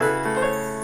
keys_07.wav